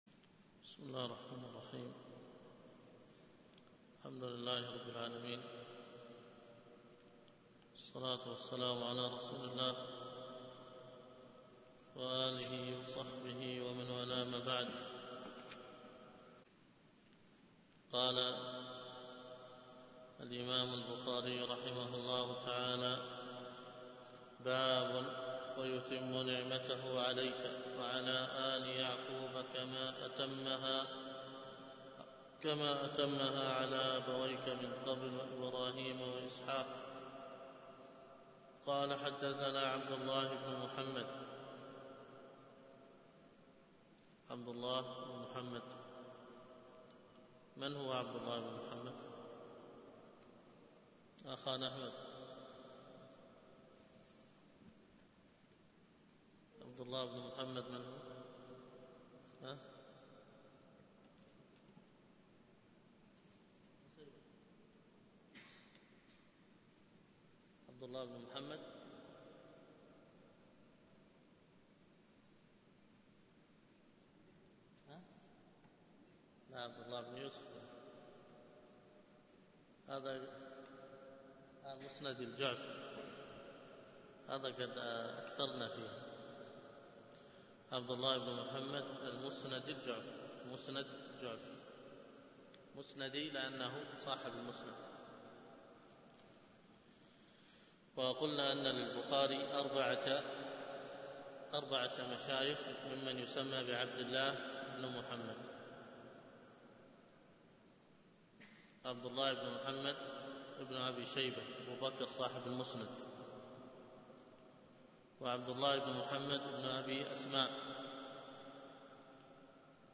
الدرس في كتاب التفسير من صحيح البخاري 9